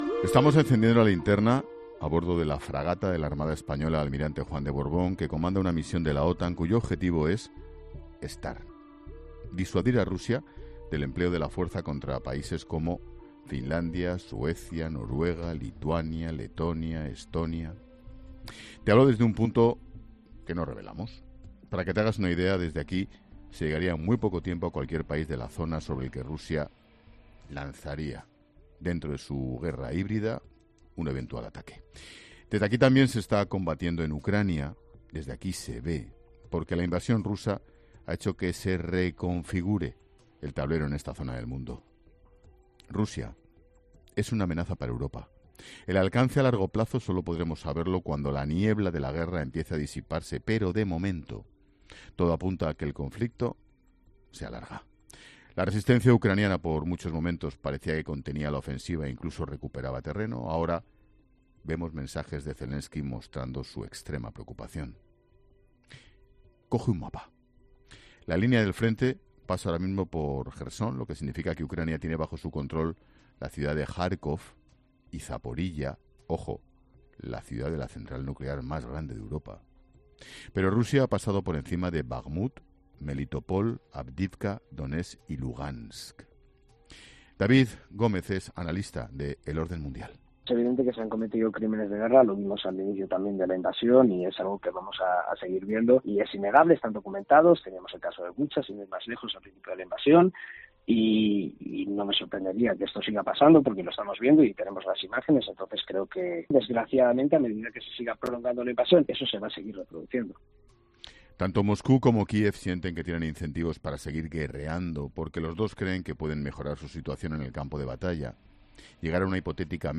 Expósito ha encendido La Linterna este lunes desde el Mar del Norte, a bordo de la Fragata Almirante Juan de Borbón, que comanda una misión de la OTAN cuyo objetivo es disuadir a Rusia del empleo de la fuerza contra países como Finlandia, Suecia, Noruega, Lituania, Letonia o Estonia. El comunicador de COPE presenta el programa desde un punto del Atlántico Norte desde el que se llegaría en muy poco tiempo a cualquier país de la zona sobre el que Rusia lanzara un eventual ataque.